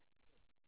osa 0918 (Monaural AU Sound Data)